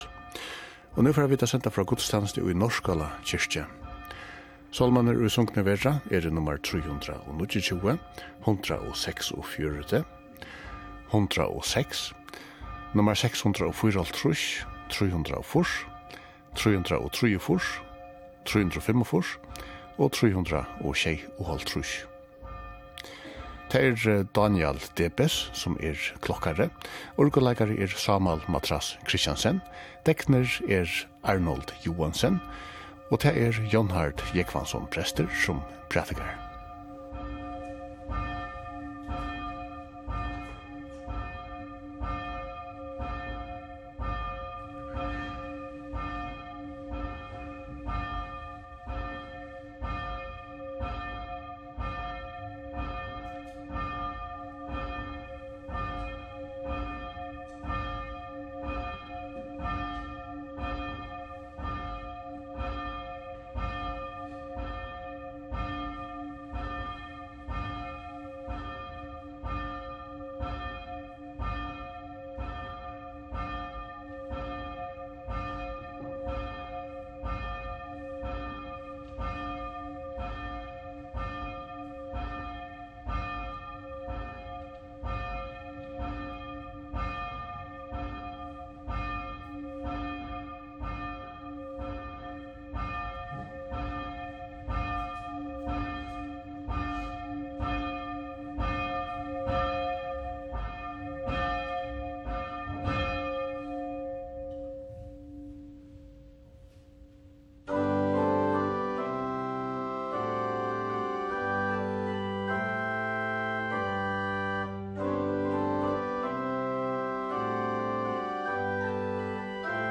Útvarpið sendir beinleiðis gudstænastu hvønn sunnudag í árinum úr føroysku fólkakirkjuni.
Sagt verður, hvør er prestur, deknur, urguleikari og klokkari, og hvørjir sálmar verða sungnir.